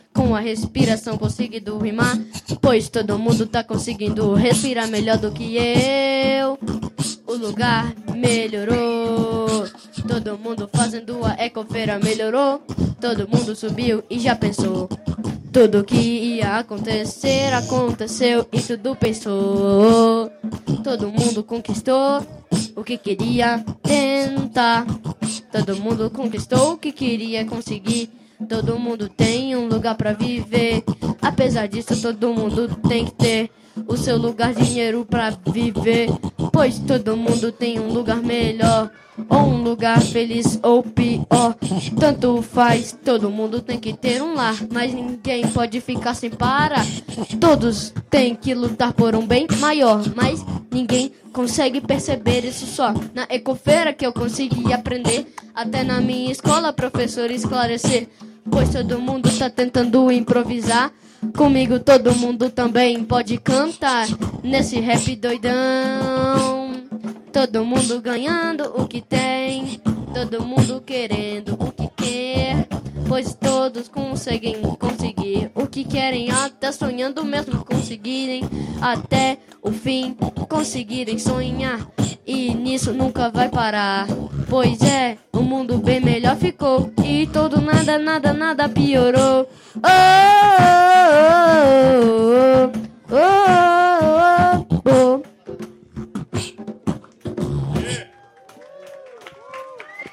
Aqui, alguns momentos dessa edição especial que reuniu os encontros ATIVISMO DIGITAL e a MOSTRA de CULTURA E SAÚDE: